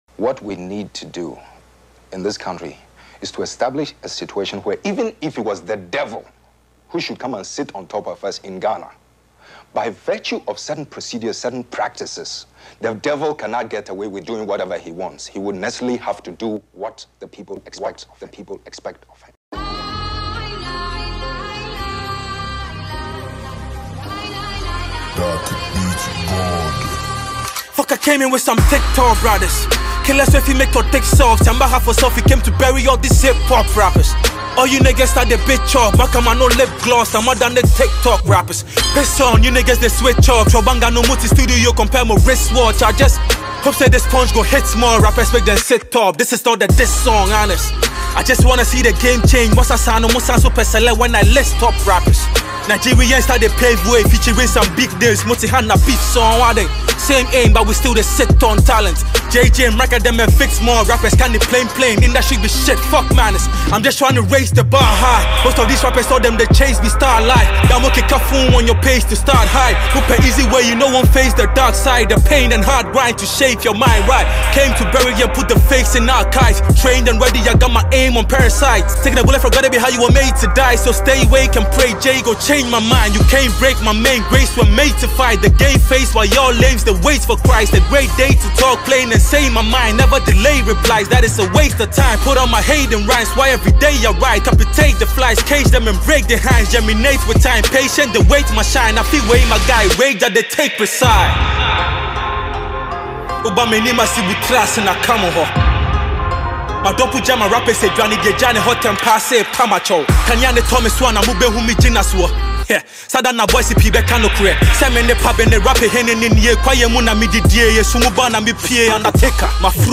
Ghana MusicMusic
Ghanaian rapper